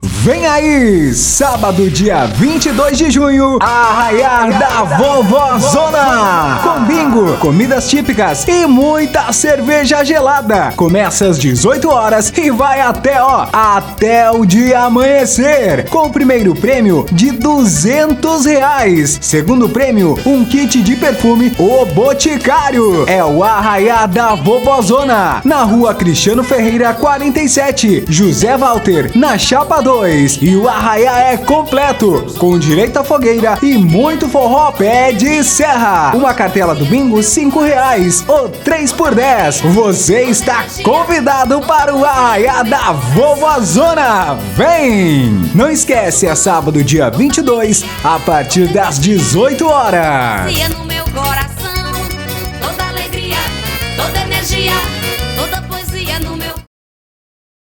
Chamada: